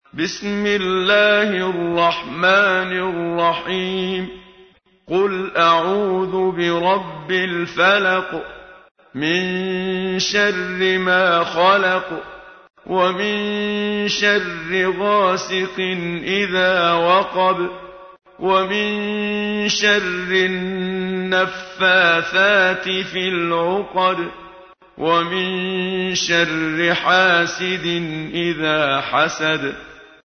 تحميل : 113. سورة الفلق / القارئ محمد صديق المنشاوي / القرآن الكريم / موقع يا حسين